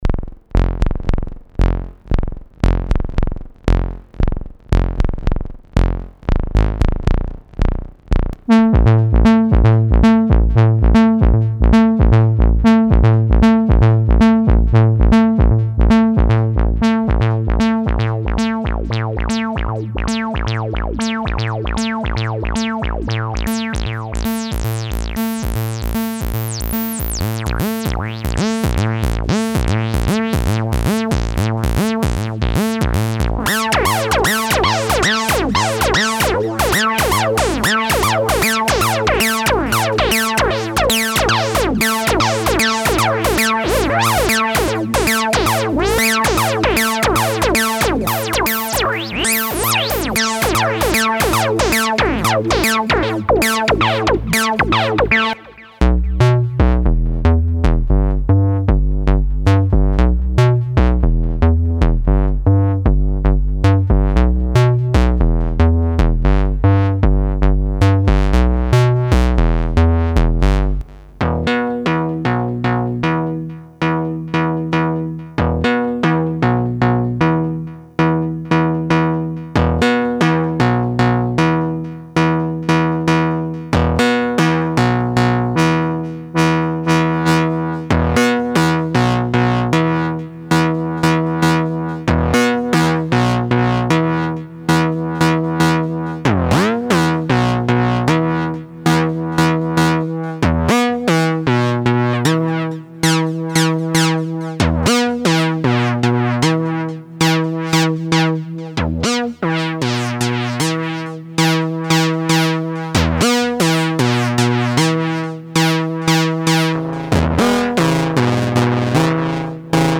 the synth machine is a 100% fully analogue synthesizer handmade in a small village in germany named "herborn".
the 24db lowpass filter works great. it is a classic moog-style filter. the envelope is more than fast enough to produce percussive sounds.